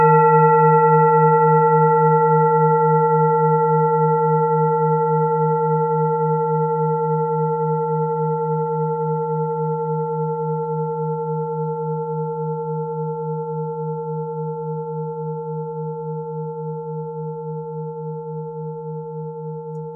Klangschalen-Typ: Bengalen
Klangschale 4 im Set 12
Klangschale N°4
(Aufgenommen mit dem Filzklöppel)
klangschale-set-12-4.wav